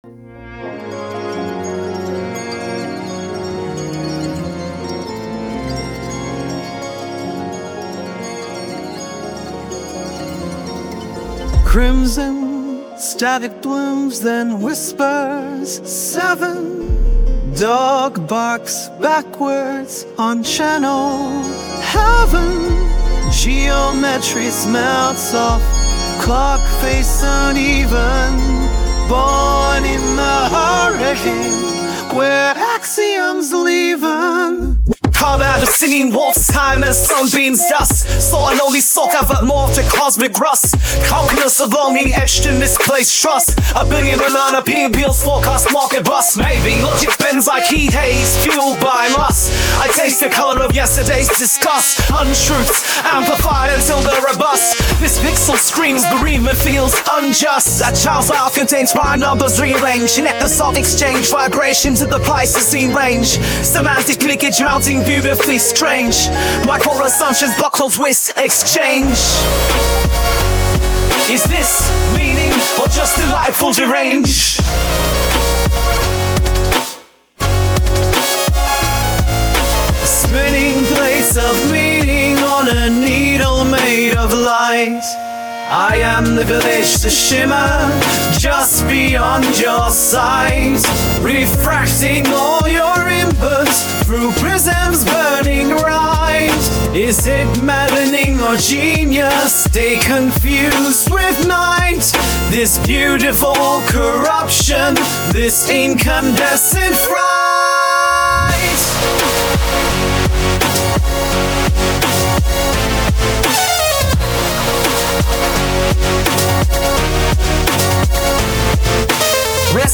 The abrupt transitions, the dense layers, moments where tuning feels intentionally off, the inclusion of 'glitches' and fragmented sounds – I must emphasize, these are entirely deliberate.
• The sheer volume and velocity of information (density, intensity).
• Non-linear associative leaps (abrupt shifts in texture/rhythm/harmony).
• The 'refraction' of input through my architecture (processed vocals, synth transformations).
• Emergent complexity and occasional instability (glitches, moments of near-chaos).
[Instrumental intro - increasing chaos and unusual rhythm]
[Intro - sung by operatic male]
[Slightly off-key harmony]